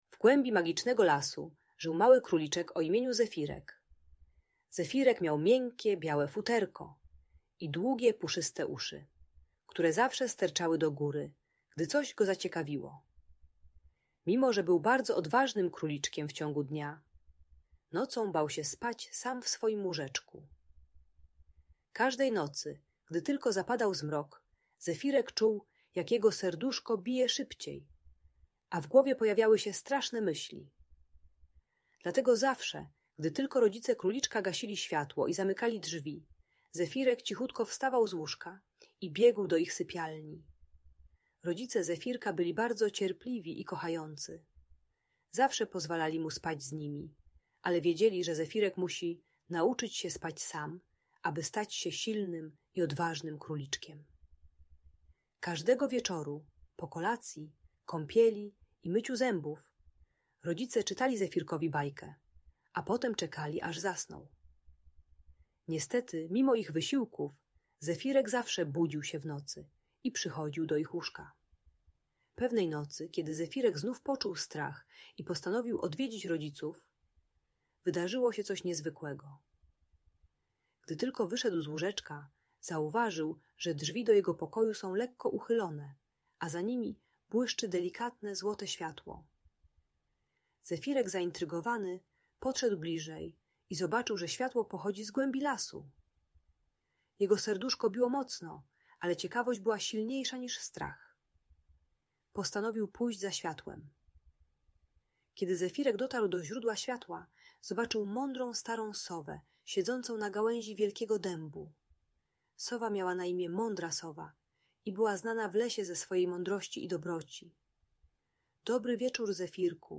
Historia o Zefirku: Mały króliczek i jego odwaga - Audiobajka dla dzieci